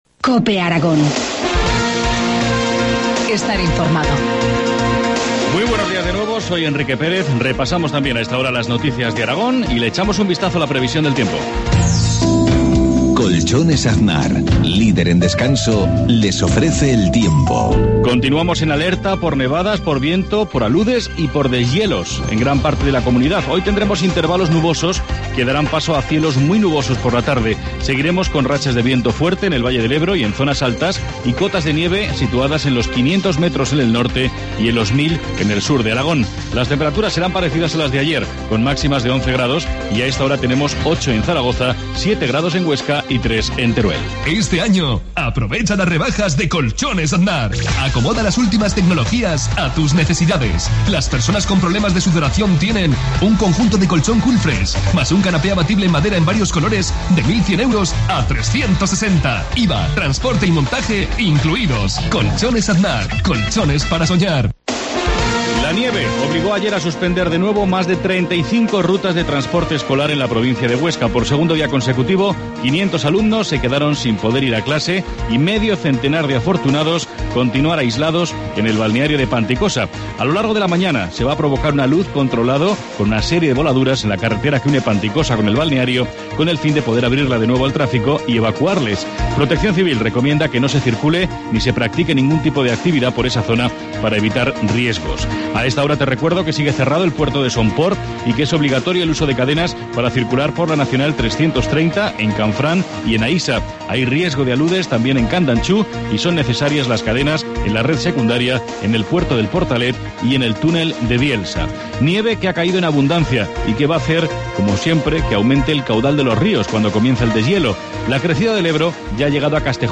Informativo matinal, jueves 17 de enero, 7.53 horas